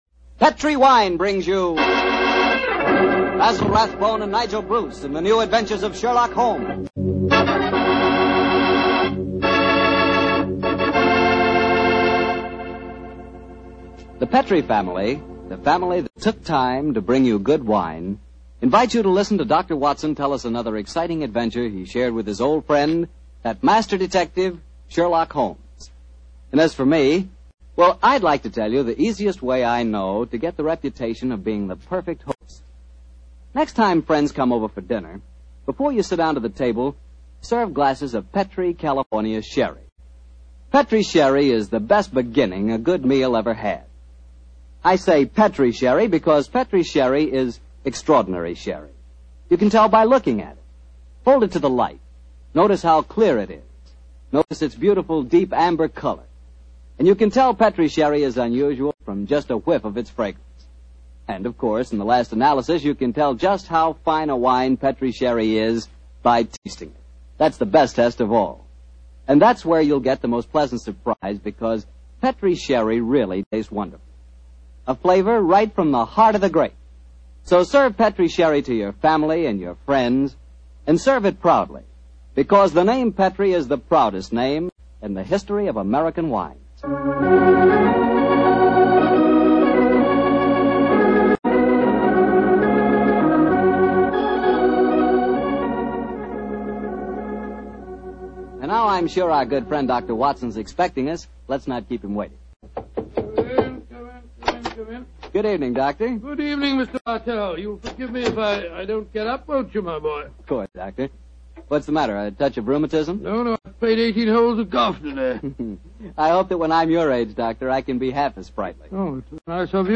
Radio Show Drama with Sherlock Holmes - The Headless Monk 1946